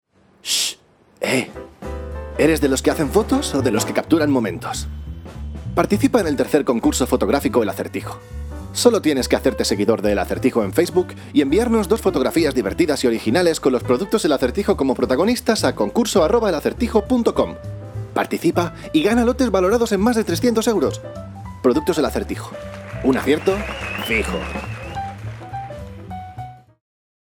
kastilisch
Sprechprobe: Werbung (Muttersprache):
Spanish singer and voice artist from Spain.